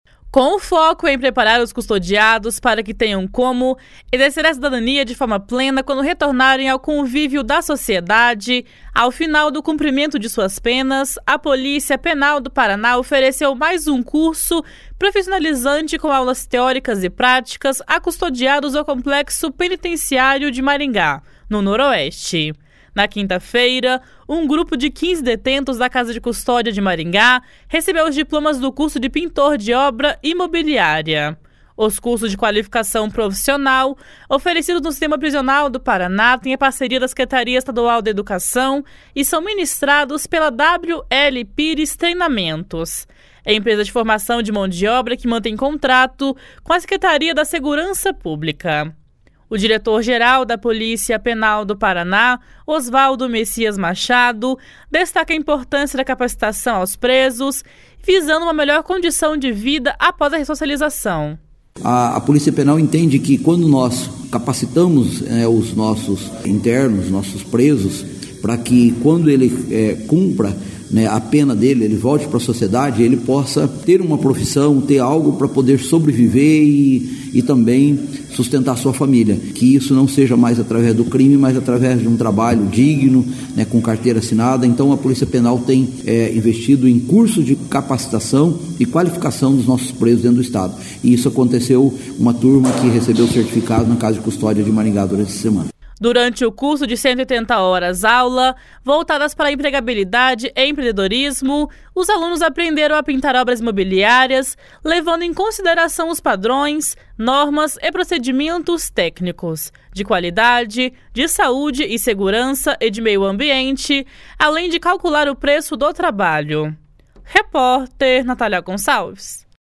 O diretor-geral da Polícia Penal do Paraná, Osvaldo Messias Machado, destaca a importância da capacitação aos presos visando uma melhor condição de vida após a ressocialização.